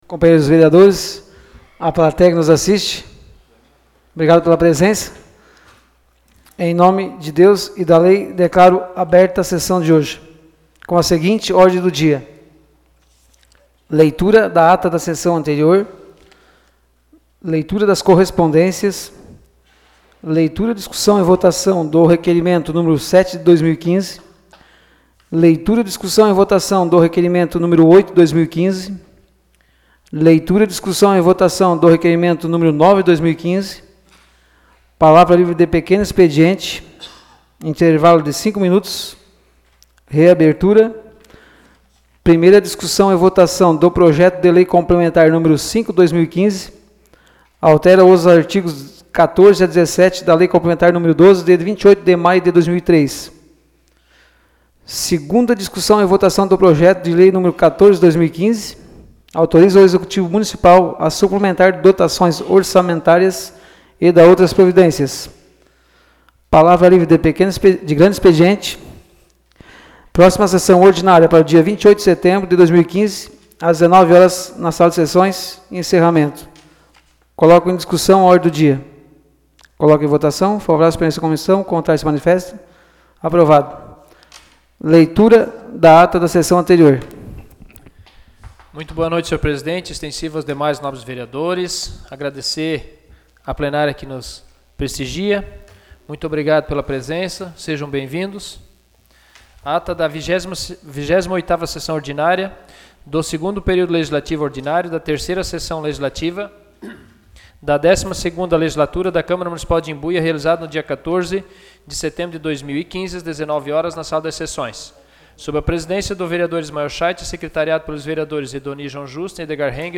Áudio da Sessão Ordinária realizada em 21 de setembro de 2015.